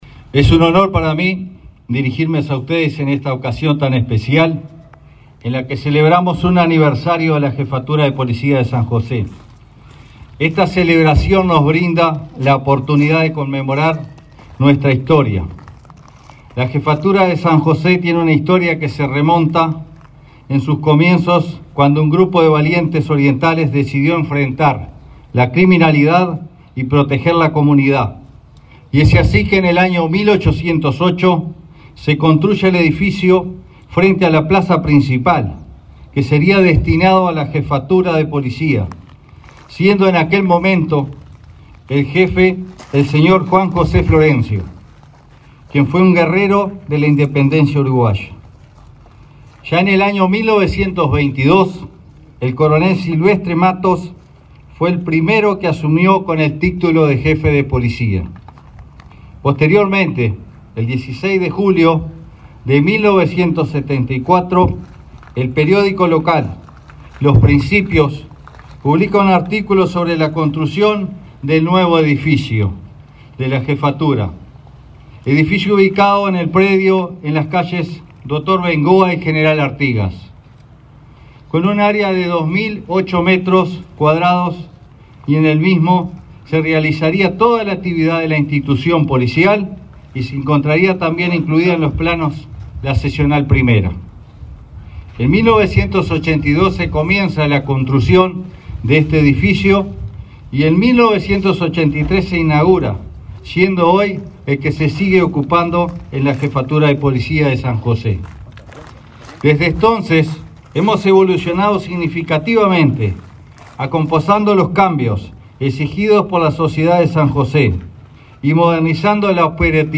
Palabras del jefe de Policía de San José, Atilio Rodríguez
Palabras del jefe de Policía de San José, Atilio Rodríguez 25/07/2024 Compartir Facebook X Copiar enlace WhatsApp LinkedIn Este jueves 25, el titular de la Jefatura de Policía de San José, Atilio Rodríguez, se expresó durante el acto conmemorativo del 150.° aniversario de esa dependencia.